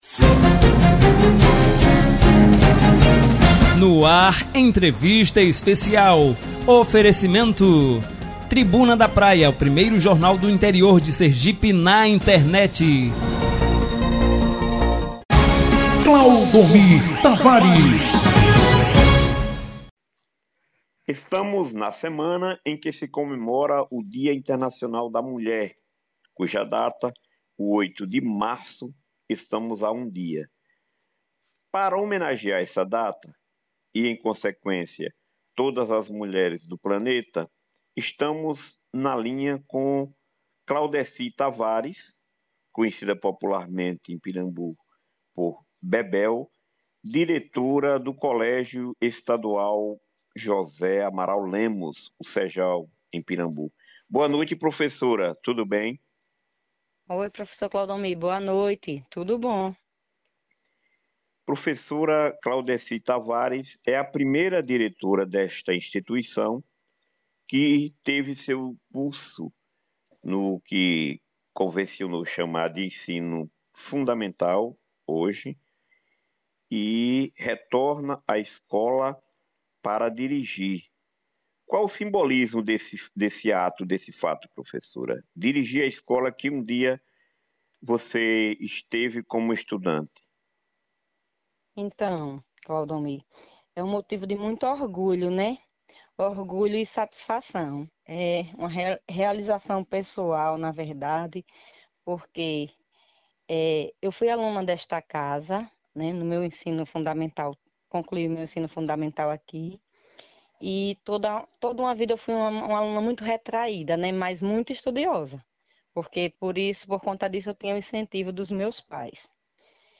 Confira abaixo na íntegra os áudios da entrevista liberados na manhã de hoje pela emissora: